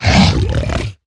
Minecraft Version Minecraft Version 25w18a Latest Release | Latest Snapshot 25w18a / assets / minecraft / sounds / mob / hoglin / attack2.ogg Compare With Compare With Latest Release | Latest Snapshot
attack2.ogg